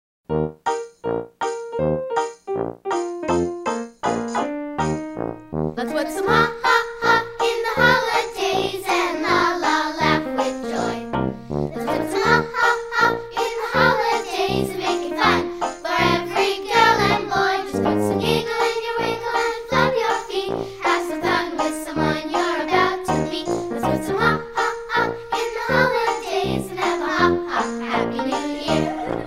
▪ The full-length music track with vocals.
Demo MP3